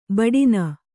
♪ baḍina